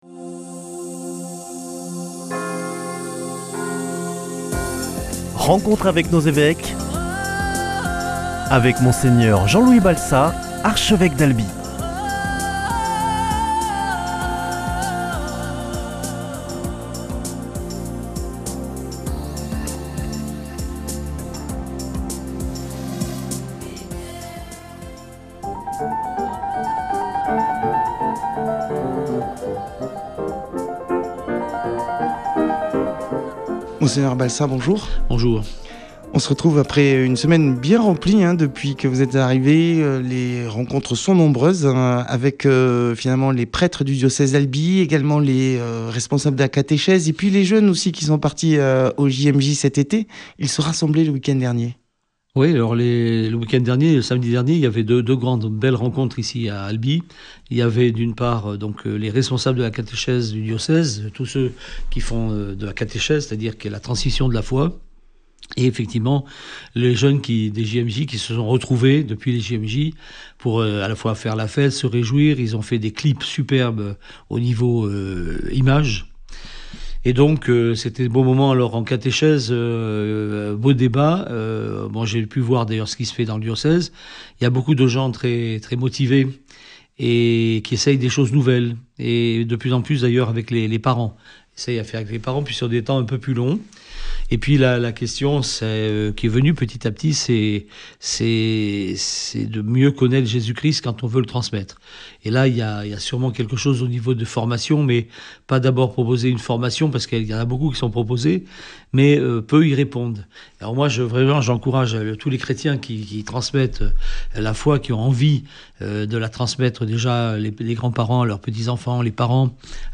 rencontre Monseigneur Jean-Louis Balsa, évêque d’Albi, fraichement installé. Ensemble, ils évoquent la position de l’Eglise sur la fin de vie.